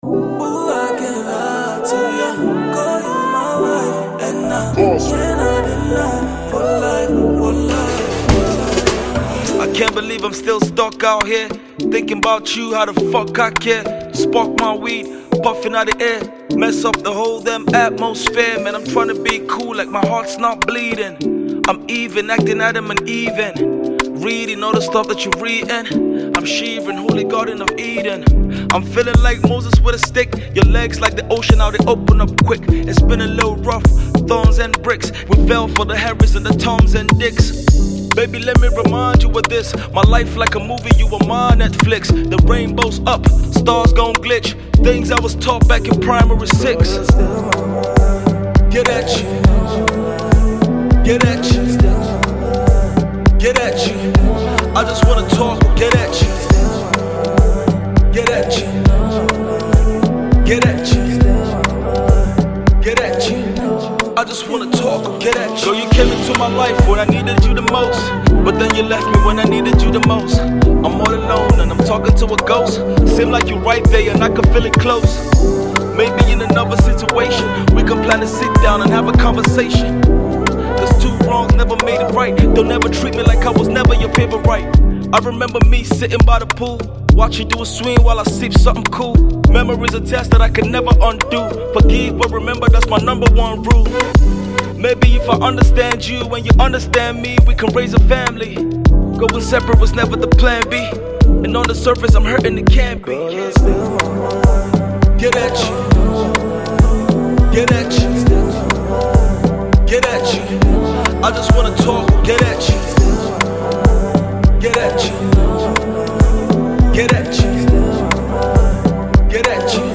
heartfelt song